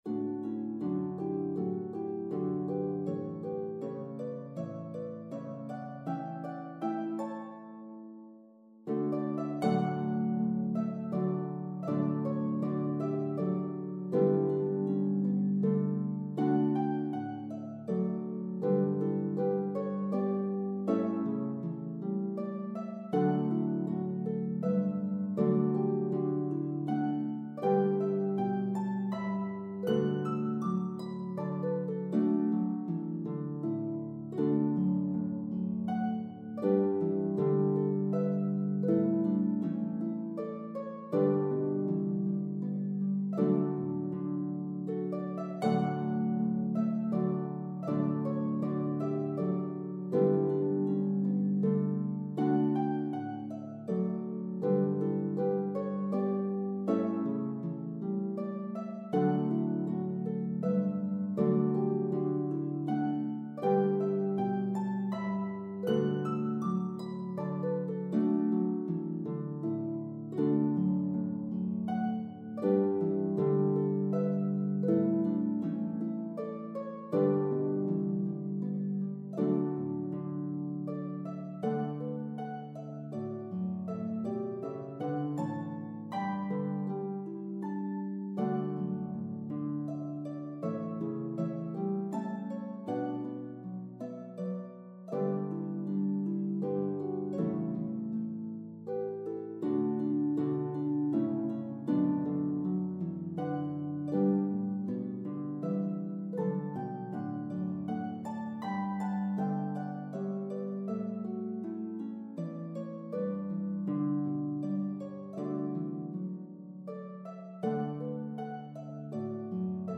The melody is divided evenly between parts.